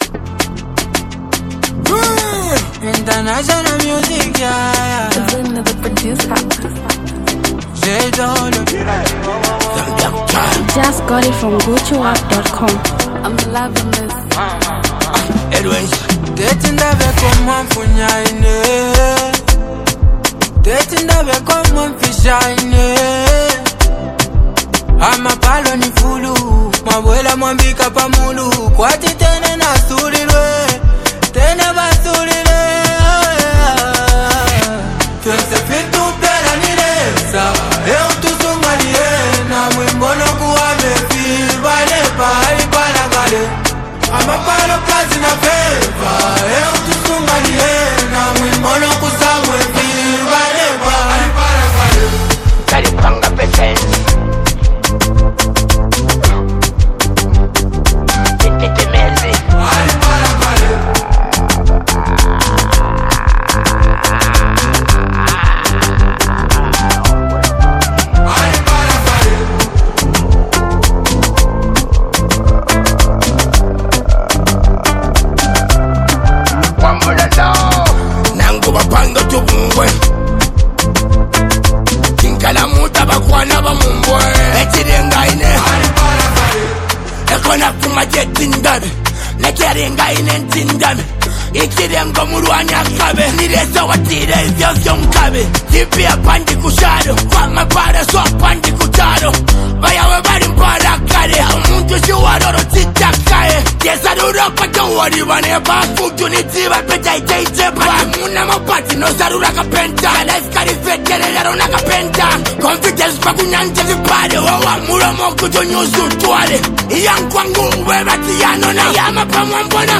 rap style
unique flow.